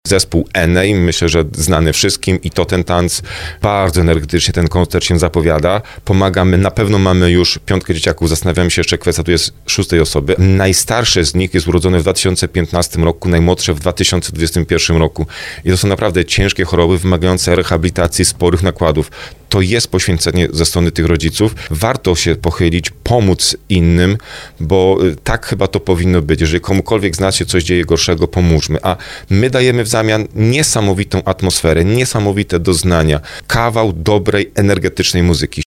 Mówił o tym w audycji Słowo za Słowo wójt gminy Skrzyszów Marcin Kiwior.